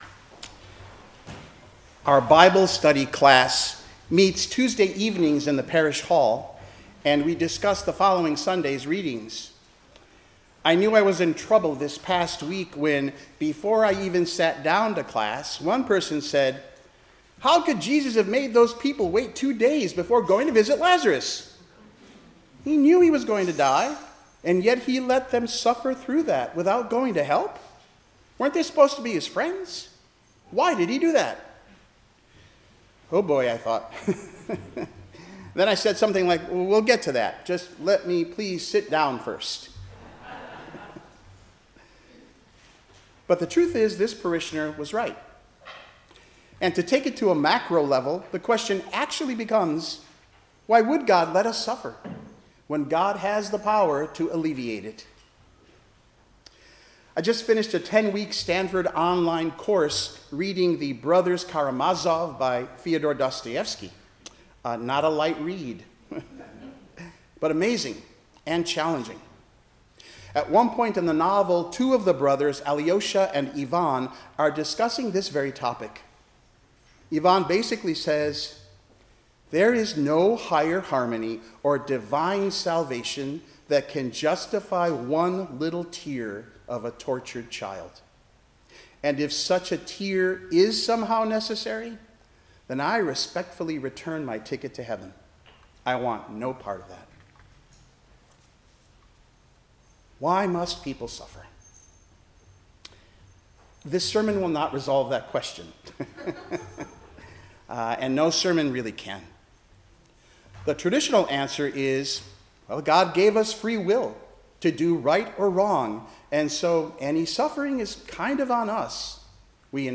10:00 am Service